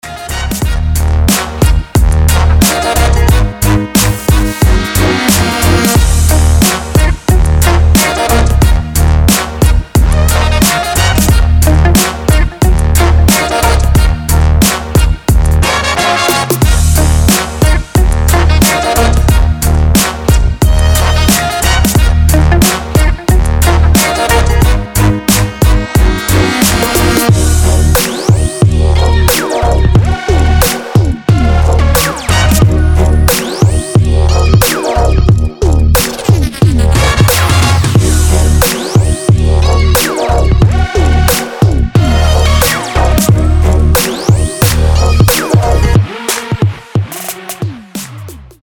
• Качество: 320, Stereo
Electronic
без слов
труба
instrumental hip-hop